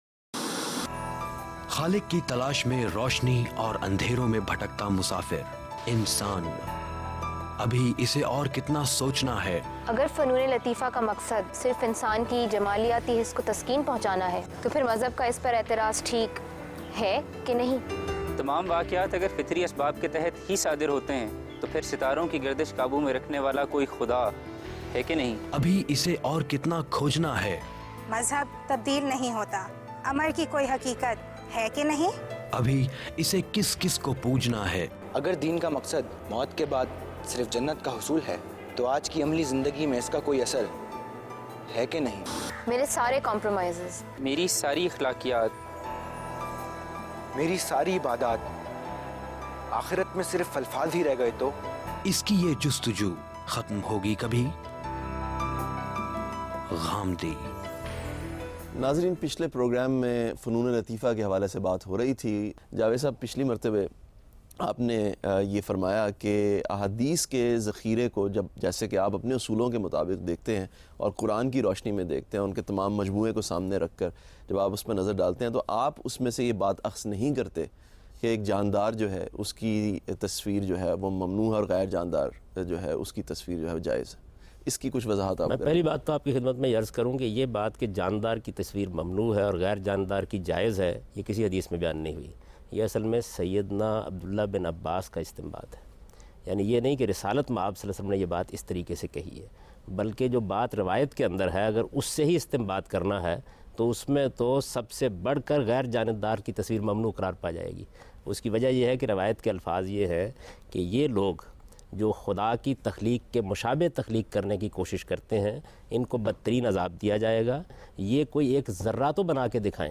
Questions and Answers on the topic “Islam and Fine Arts” by today’s youth and satisfying answers by Javed Ahmad Ghamidi.